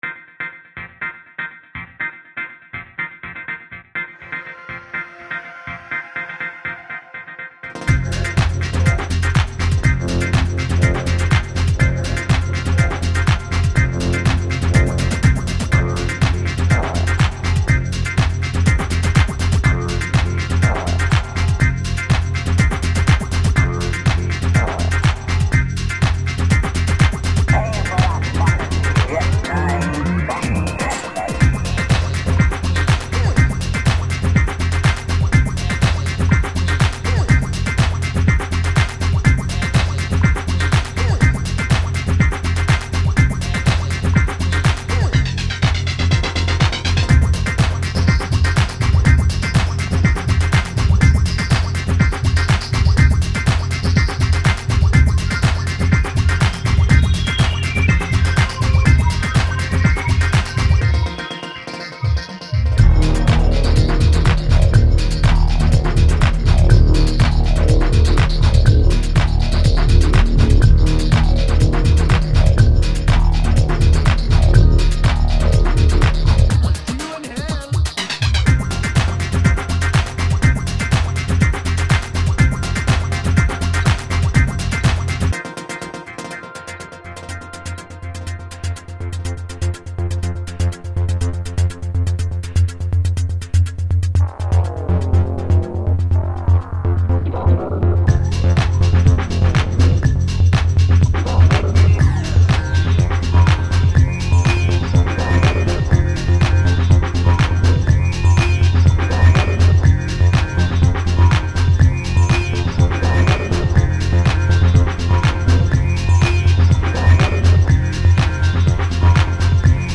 techno pop